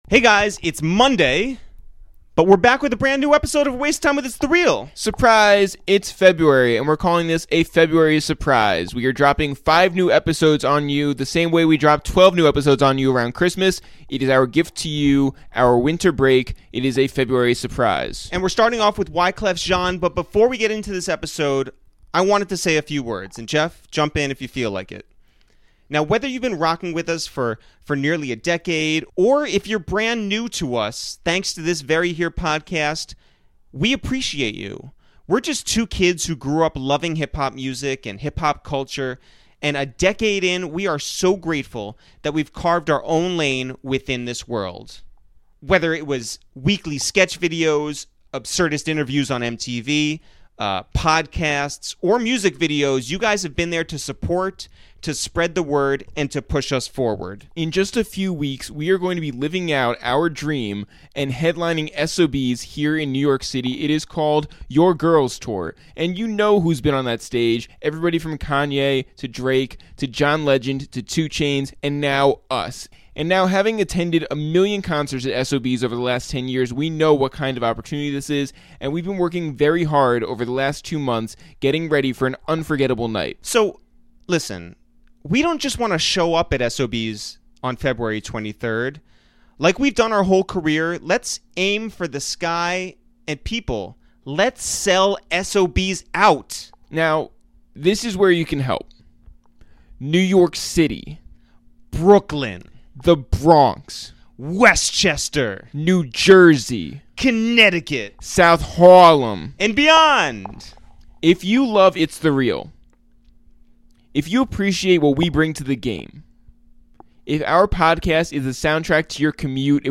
In a wide-ranging conversation, we talk about his collaborations with The Rock, Kenny Rogers, Destiny's Child, Jin tha MC, Mary J Blige, Canibus, Daryl Hall and more.